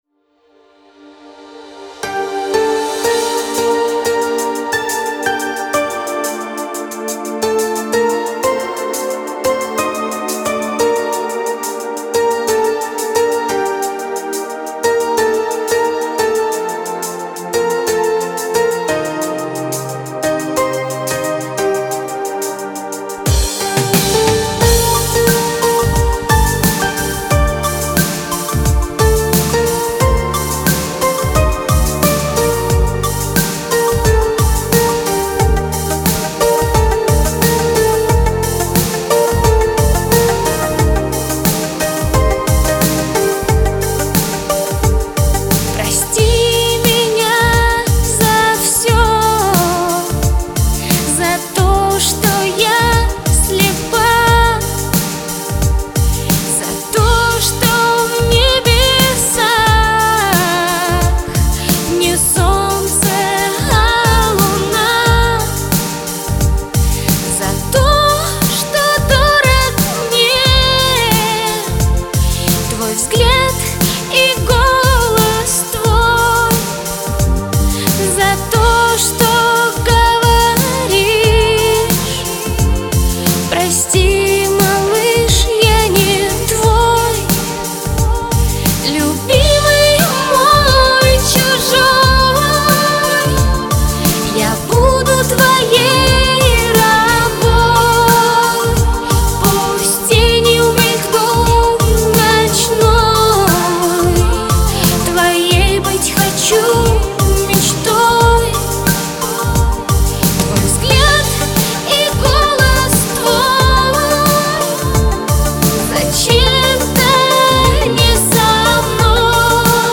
медленные песни , лиричные песни , русские медляки